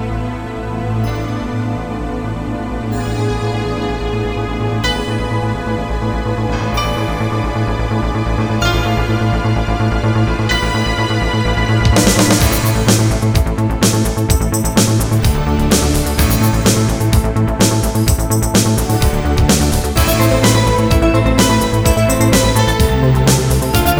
Minus Guitars Pop (1980s) 4:36 Buy £1.50